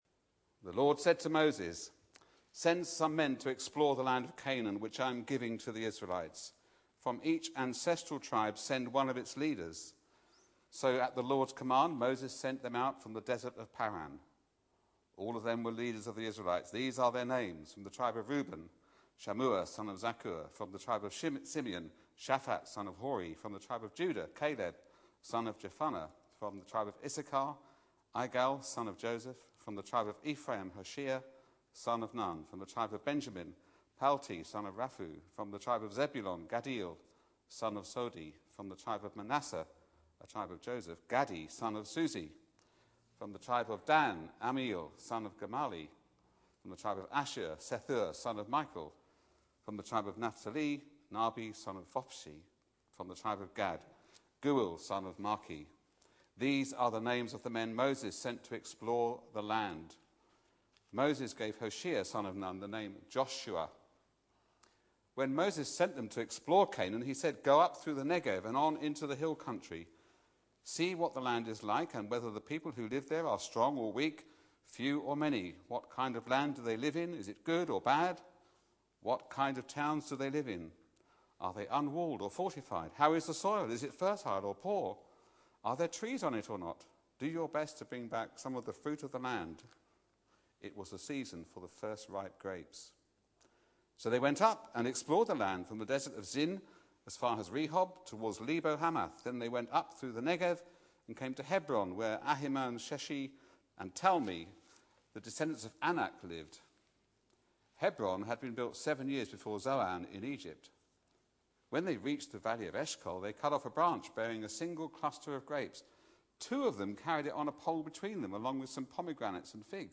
Back to Sermons Rebellion